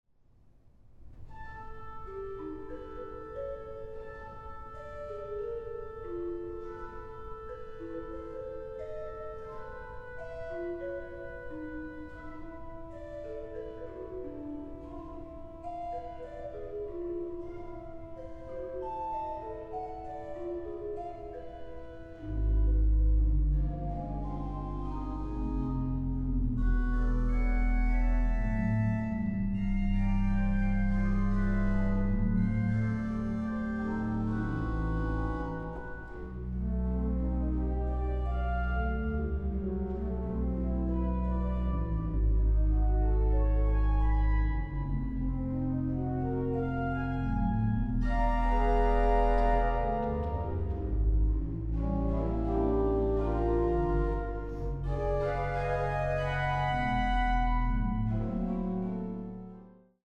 Orgel
Aufnahme: Het Orgelpark, Amsterdam, 2023